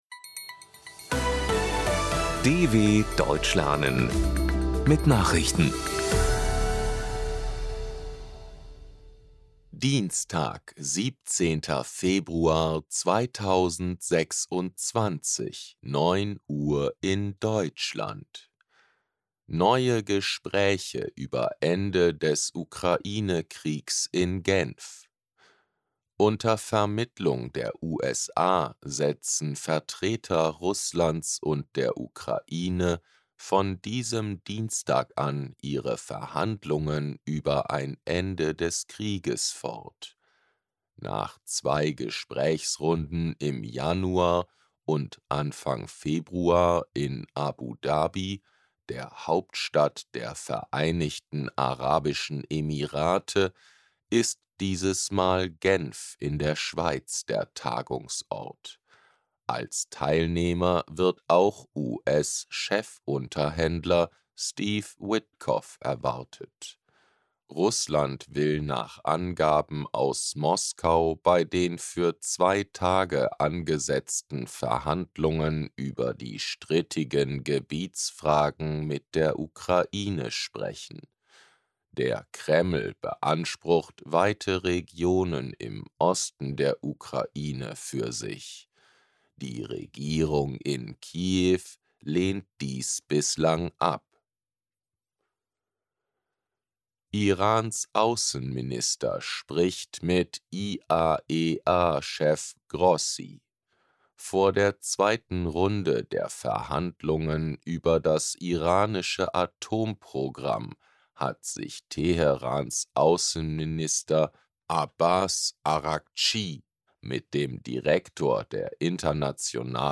17.02.2026 – Langsam Gesprochene Nachrichten
Trainiere dein Hörverstehen mit den Nachrichten der DW von Dienstag – als Text und als verständlich gesprochene Audio-Datei.